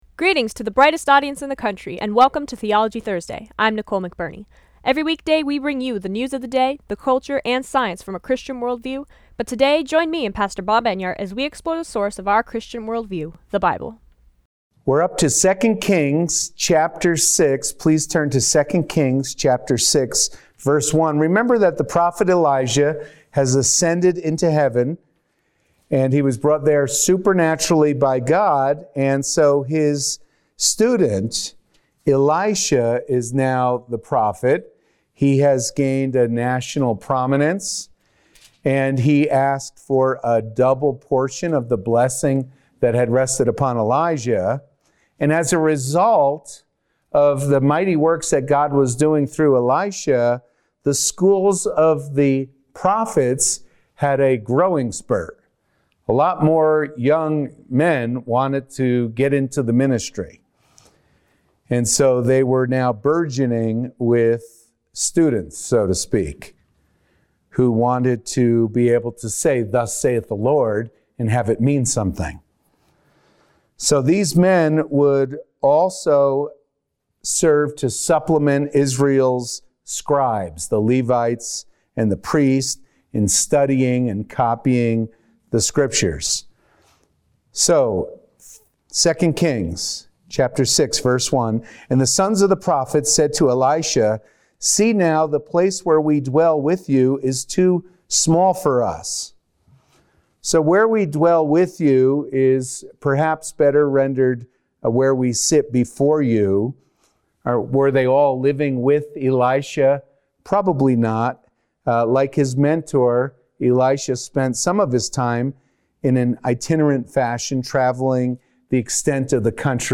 Juanita Broaddrick Interview: Rare Media Appearance